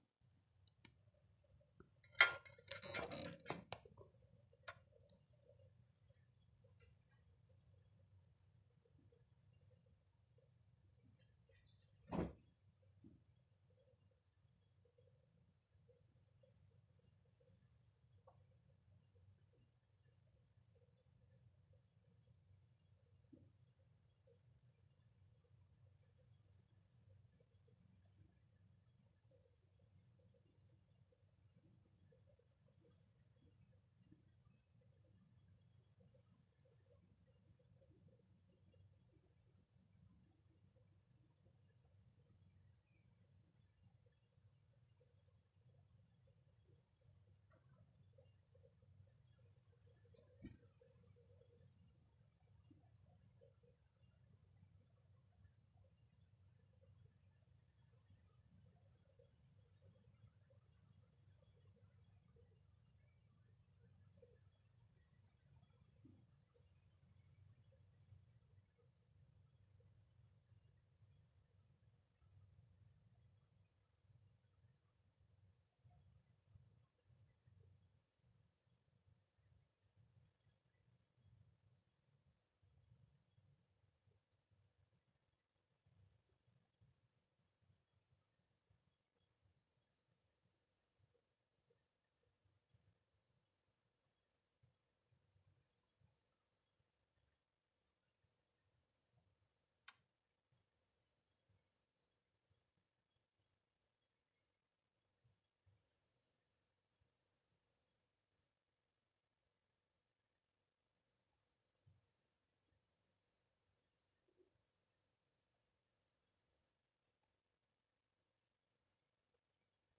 Piep-Geräusche aus dem Gehäuse (Mit Audio Aufnahme) | ComputerBase Forum
Bei dieser Aufnahme ist es noch eindeutiger.
Die Geräusche intensivieren sich immer, wenn ich die Maus bewege, ich versteh den Zusammenhang nicht.
Klingt für mich auch nach Spulenfiepen.